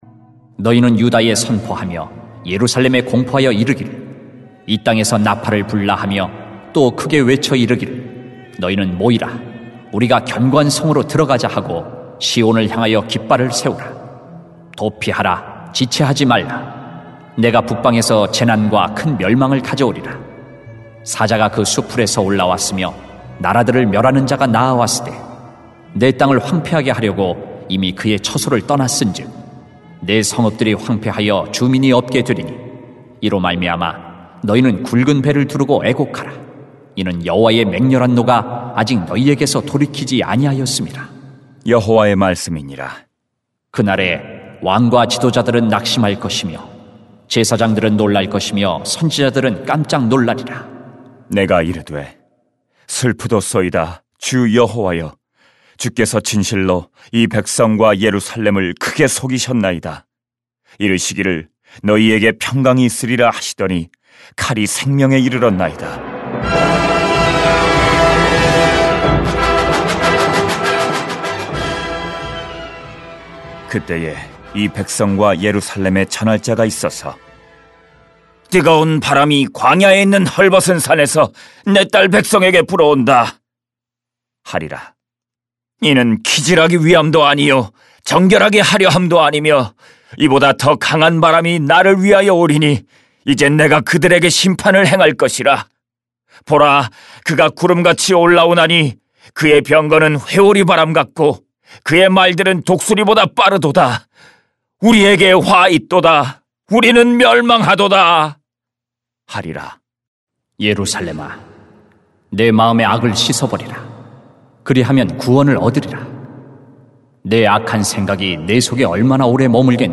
[렘 4:5-18] 그리하면 구원을 얻으리라 > 새벽기도회 | 전주제자교회